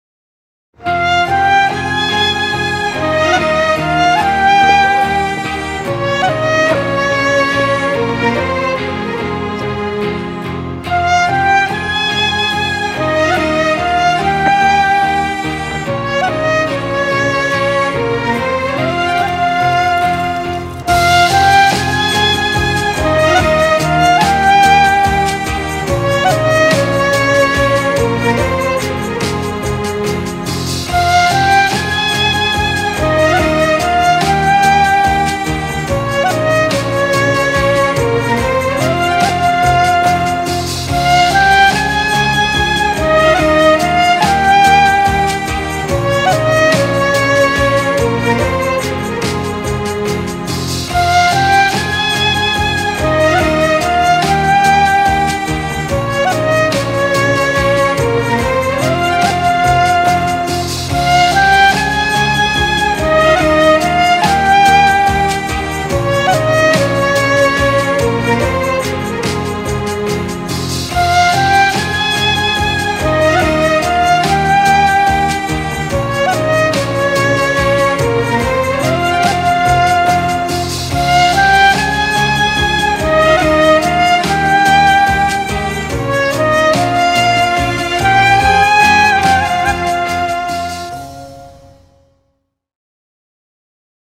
duygusal hüzünlü rahatlatıcı fon müziği.